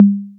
808 Lo Conga.WAV